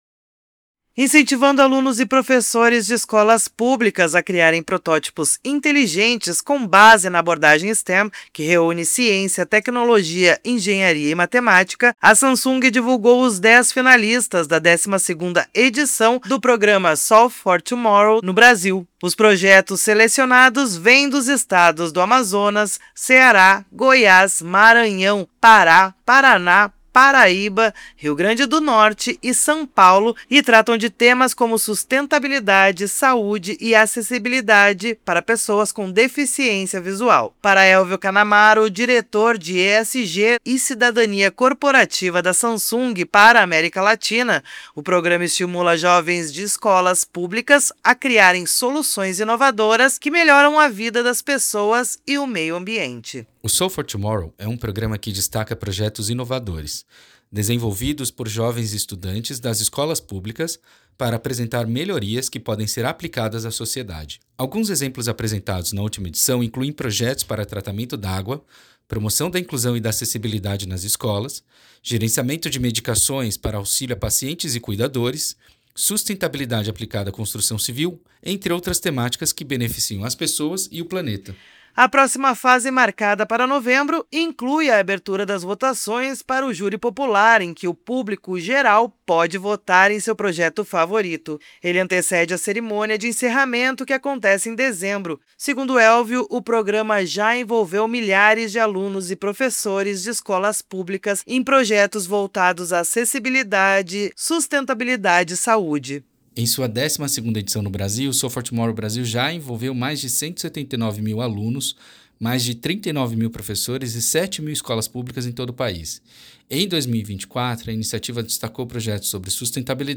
Materiais de Imprensa > Radio Release